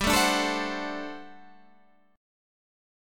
GM7sus2sus4 chord